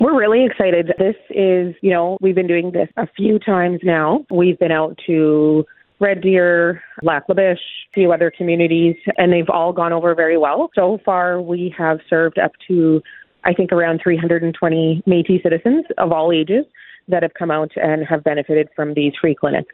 Bobbi Paul-Alook, Secretary of Health and Seniors for the MNA says they are really excited to bring the free dental services to Slave Lake, as the success rate was high in communities like Lac La Biche and Red Deer.